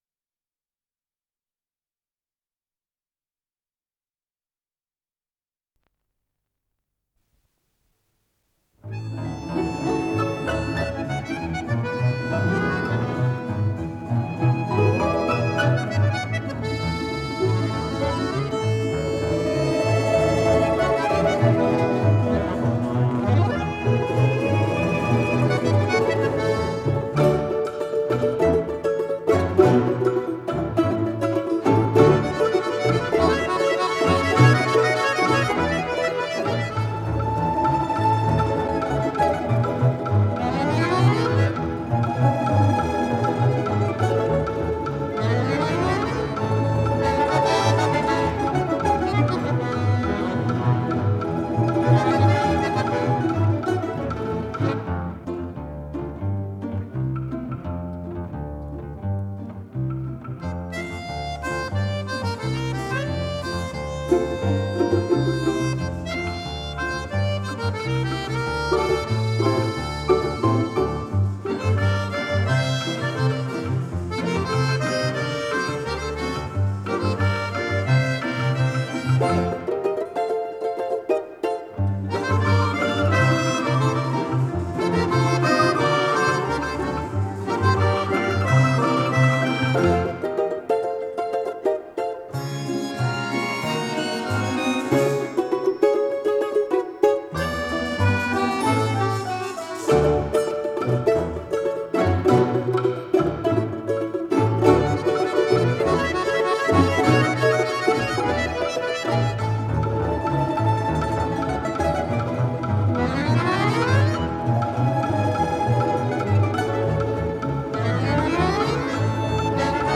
Скорость ленты38 см/с
МагнитофонМЭЗ-109М